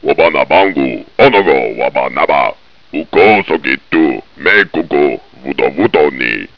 The Mission Controller was so startled when the alien touched his back that he briefly reverted to his native language.
The first word is loosely translated as "Wow!", "Oh my!", "Yikeso!", or similar exclamation of surprise.
Images and sounds are taken from Cosmic Osmo and the Worlds Beyond the Mackerel , Copyright 1989, 1990, 1992, Cyan, Inc. Used by Permission.